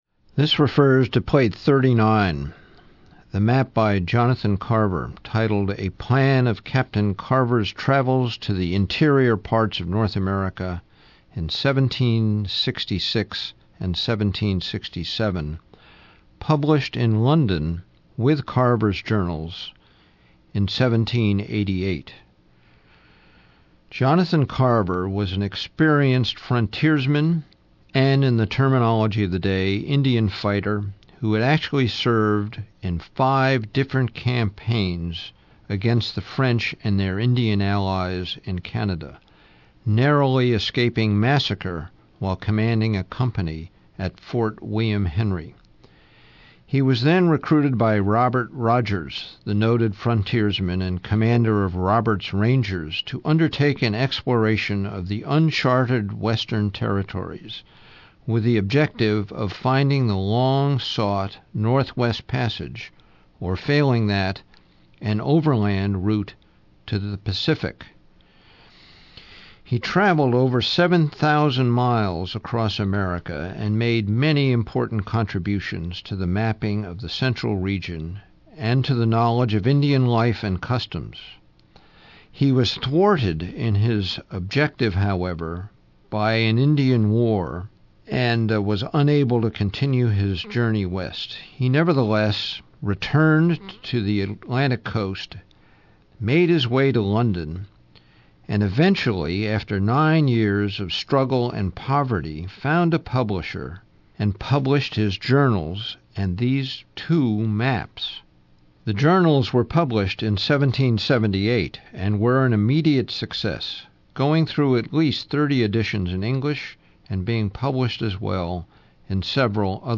Audio Tour: About Carver